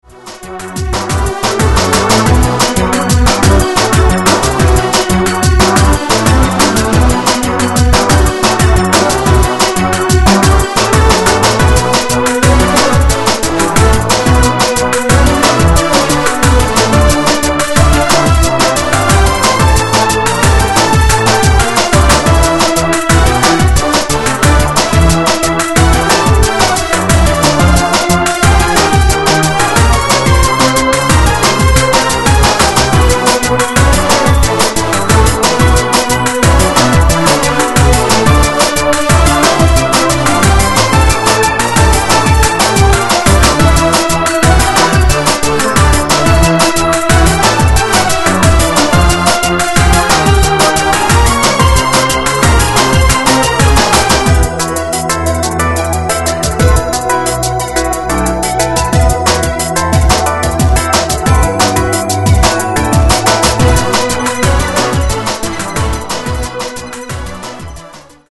DEMO 819850 bytes / 01:08 / bpm180